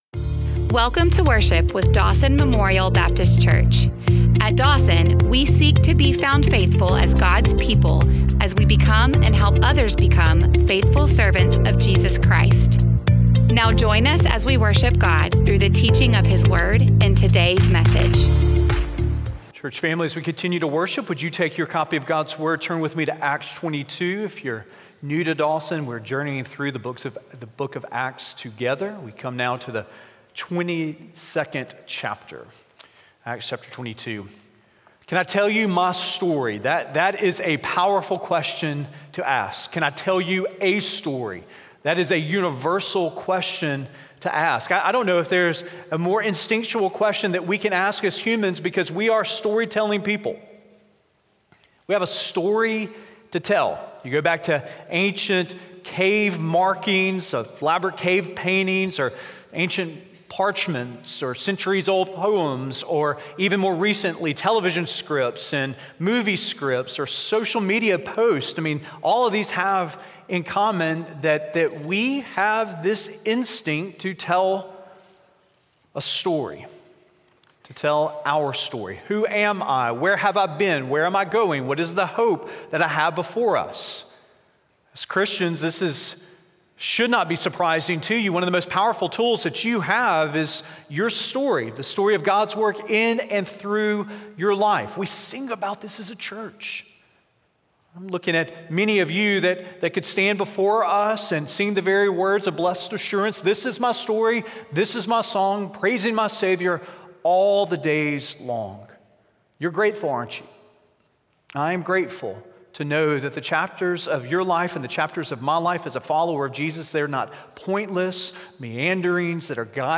Podcast1027sermon.mp3